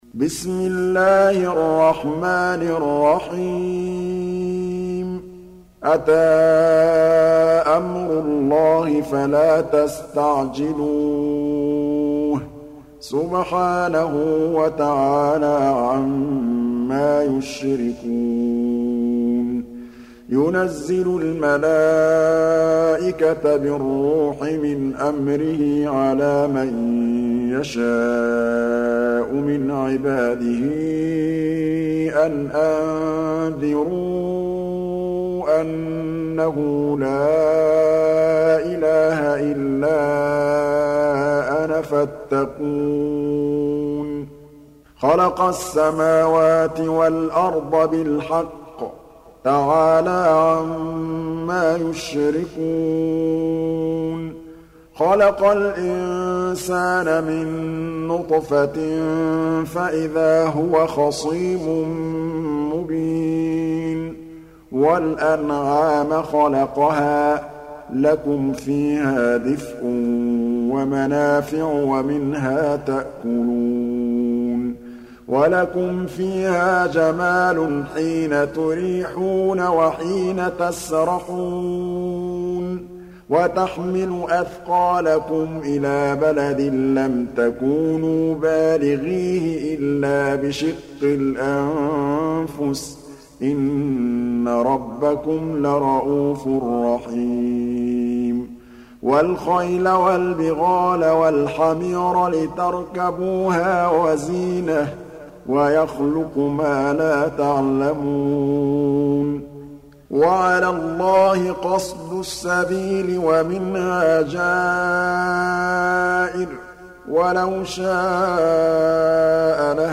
Surah Repeating تكرار السورة Download Surah حمّل السورة Reciting Murattalah Audio for 16. Surah An-Nahl سورة النحل N.B *Surah Includes Al-Basmalah Reciters Sequents تتابع التلاوات Reciters Repeats تكرار التلاوات